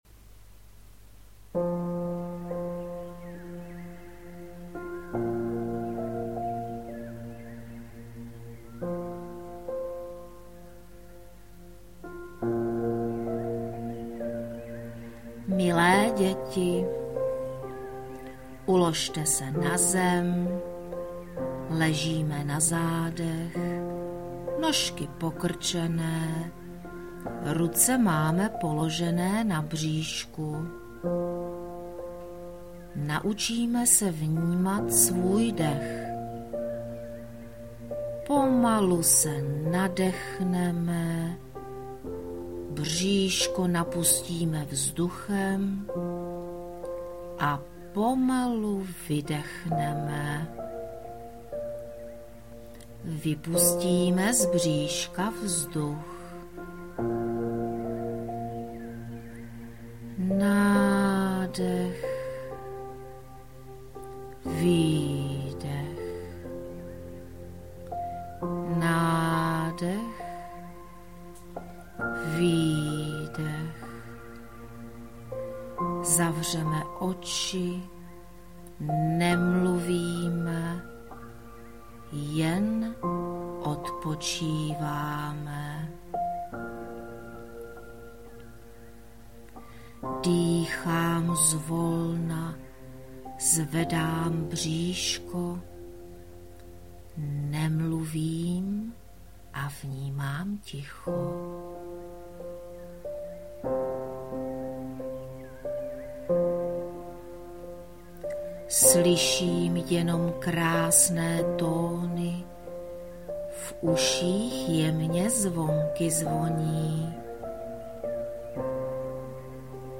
Z tohoto důvodu jsem pro Vás vytvořila relaxační CD.
Ukázka relaxace pro děti.mp3